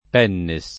[ p $ nne S ]